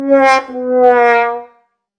Sad Trombone
The classic descending sad trombone 'wah wah wah wahhh' failure jingle
sad-trombone.mp3